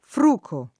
frugare
frugo [ fr 2g o ], -ghi